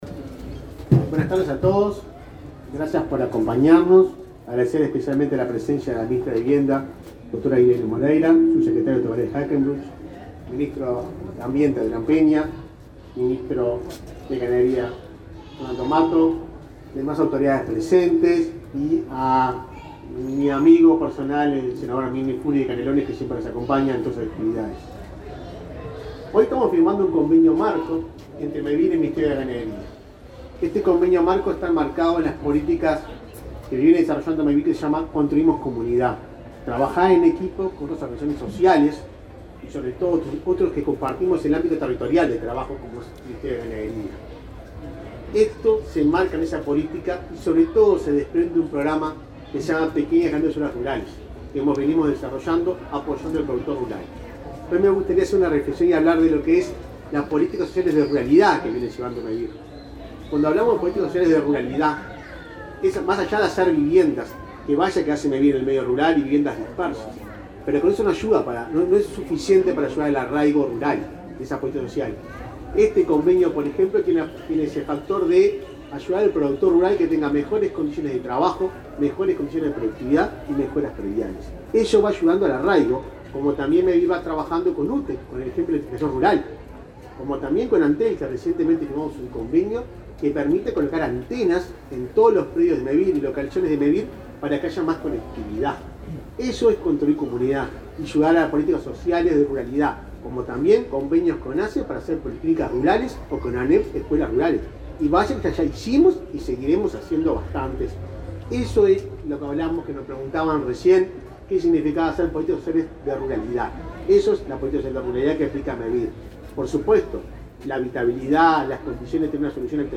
Conferencia de prensa de autoridades de Ganadería y de Mevir
Conferencia de prensa de autoridades de Ganadería y de Mevir 16/09/2021 Compartir Facebook X Copiar enlace WhatsApp LinkedIn El Ministerio de Ganadería, Agricultura y Pesca (MGAP) y Mevir firmaron un acuerdo para desarrollar políticas dirigidas a la mejora de las condiciones de trabajo, la producción y el manejo de predios. Participaron el ministro de Ganadería, Fernando Mattos,y el presidente de Mevir, Juan Pablo Delgado.